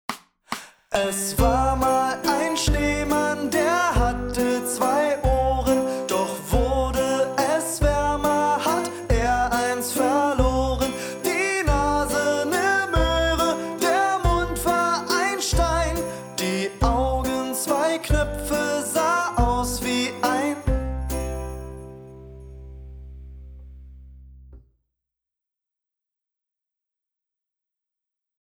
schnell + Minis